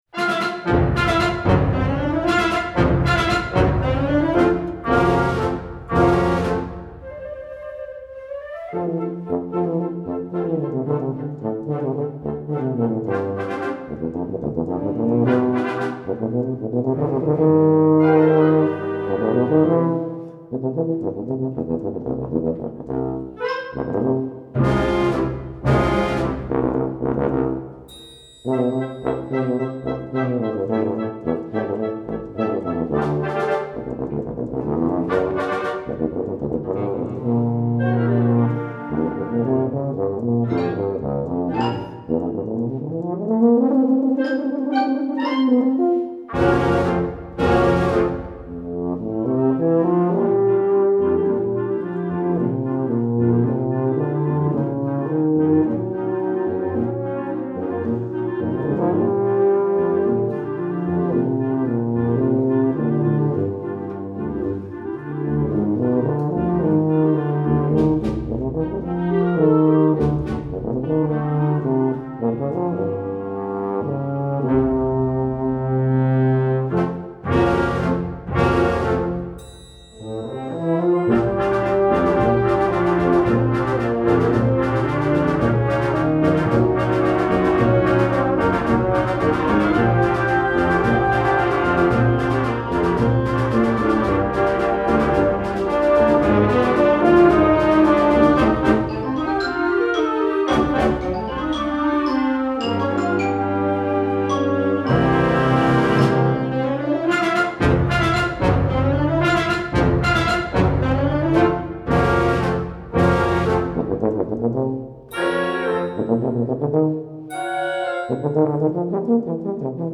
Voicing: Tuba Solo w/ Band